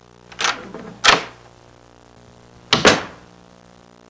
Door Opening Closing Sound Effect
Download a high-quality door opening closing sound effect.
door-opening-closing.mp3